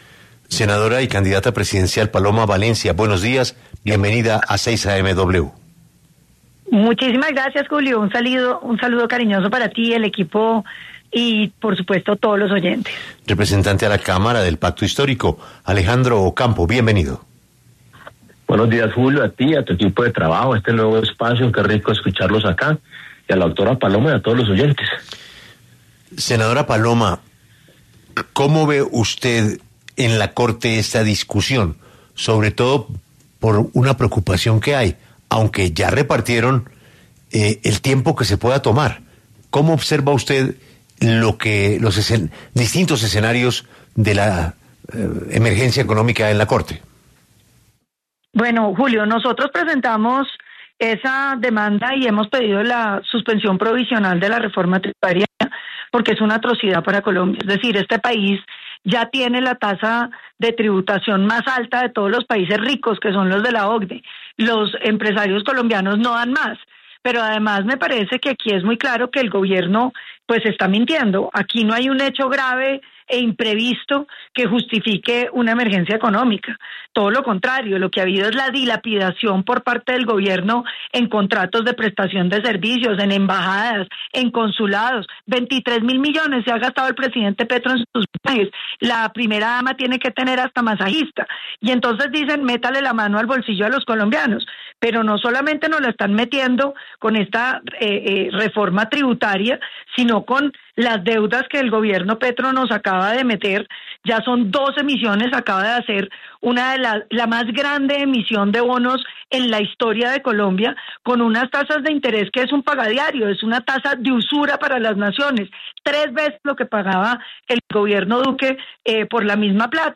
La candidata a la Presidencia de la República, Paloma Valencia, y el representante a la Cámara del Pacto Histórico, Alejandro Ocampo, debatieron en 6 AM W de Caracol sobre el futuro del decreto de emergencia económica.